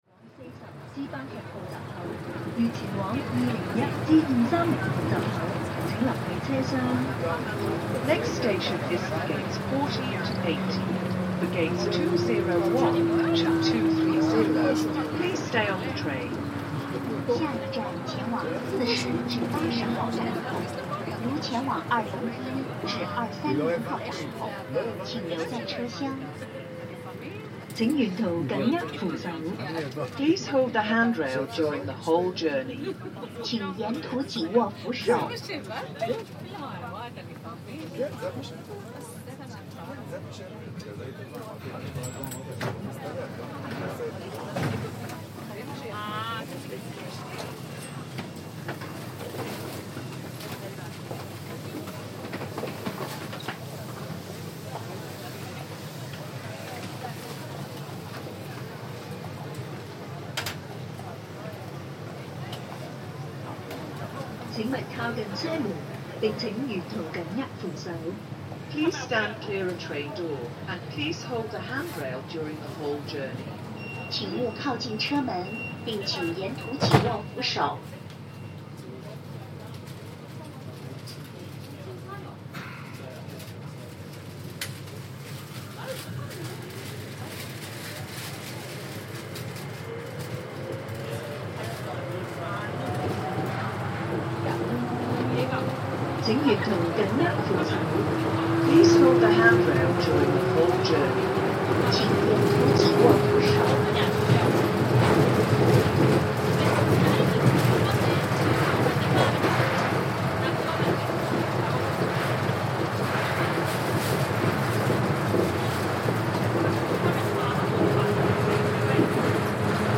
Walking through Hong Kong airport
Airport ambience recorded in Hong Kong's airport